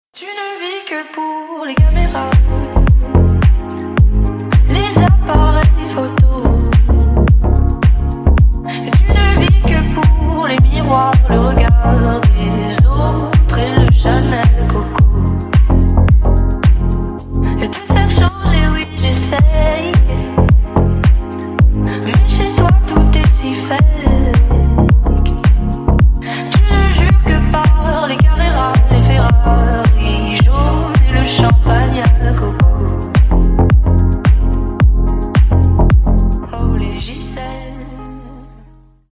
Рингтоны Ремиксы » # Танцевальные Рингтоны